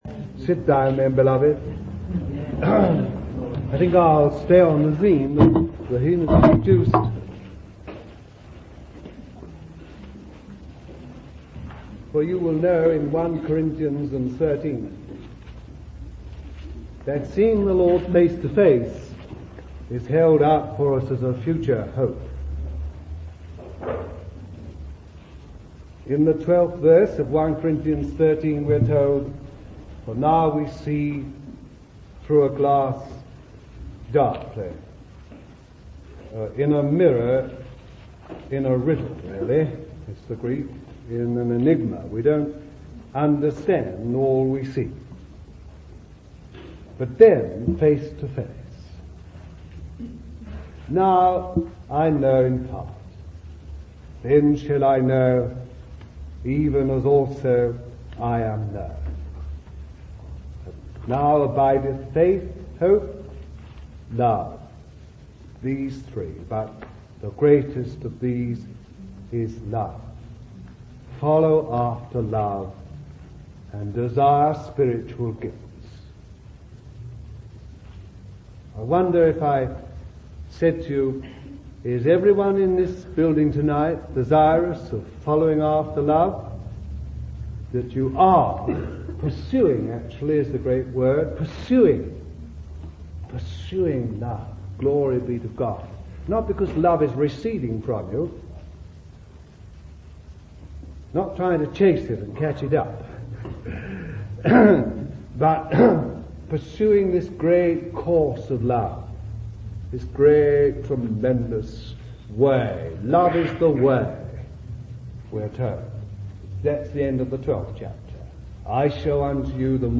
This message was delivered in London, United Kingdom in 1972 and deals with the power of God unto salvation. People's faith has to stand in the power of God and not in a theology or a creed.